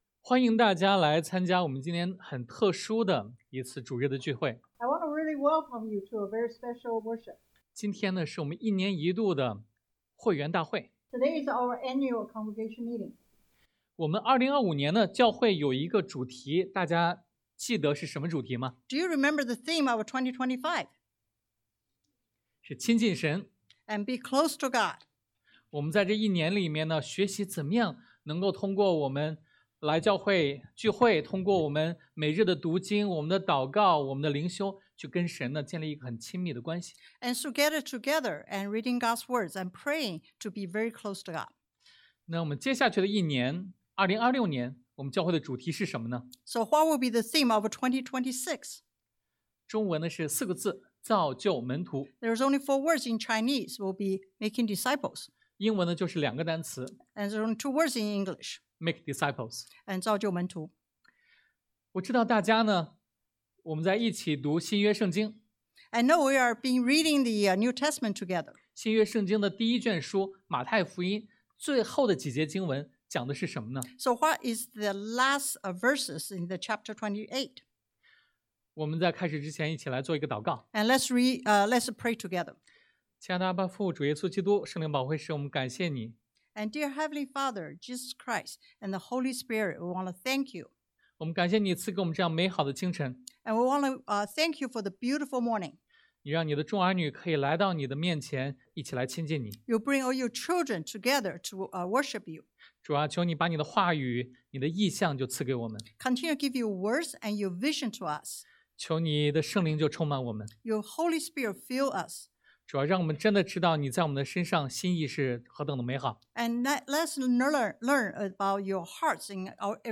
马太福音 Matthew 28:19-20 Service Type: Sunday AM Four Essential Elements 四个基本要素 1.